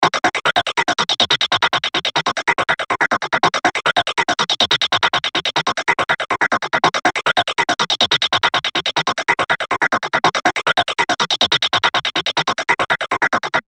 60_s Space.wav